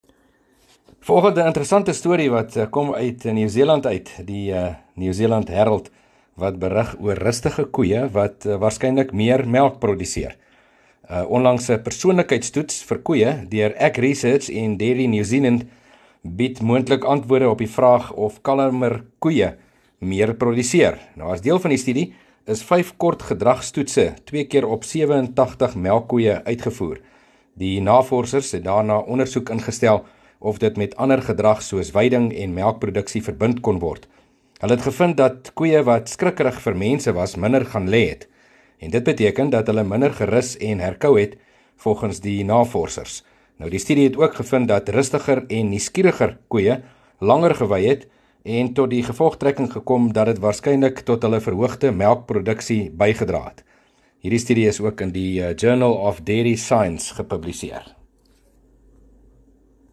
Landbou Insetsels